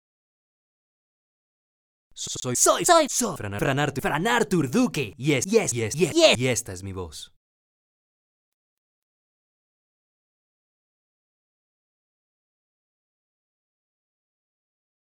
Mi voz tiene gran versatilidad de registros en tonos y ritmo.
Sprechprobe: Industrie (Muttersprache):
I am an actor and speaker with more than a decade of experience in commercial speech and in the interpretation of characteristic characters, who form the essence of an artistic and provocative work. My voice has great versatility of registers in tones and rhythm.